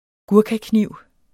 Udtale [ ˈguɐ̯kaˌkniwˀ ]